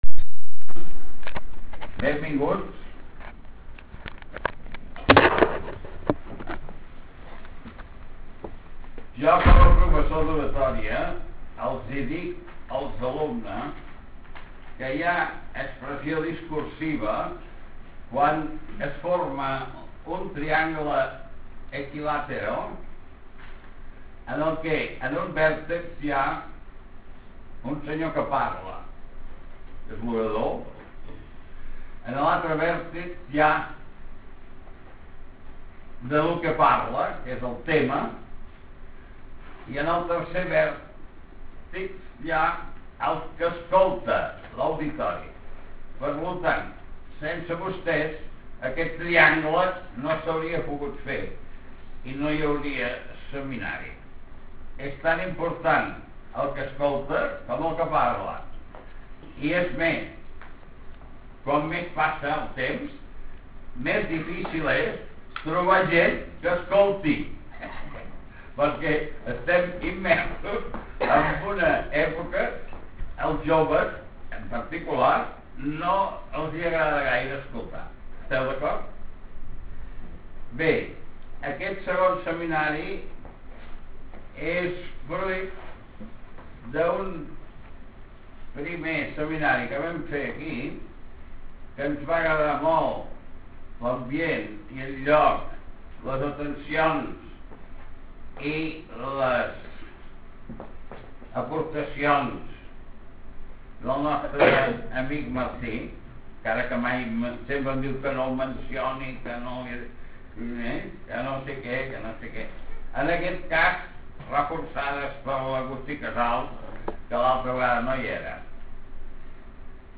Taules rodones: – TAULA DEMÒSTENES